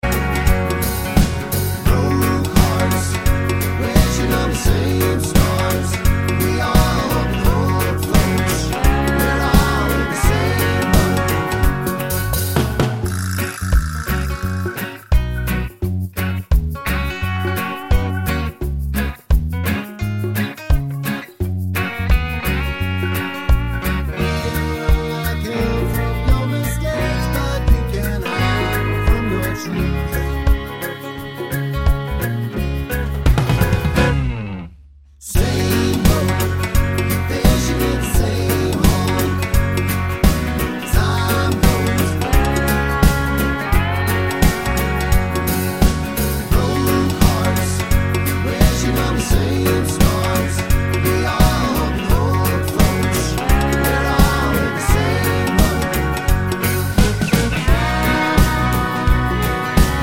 no Backing Vocals Country (Male) 3:05 Buy £1.50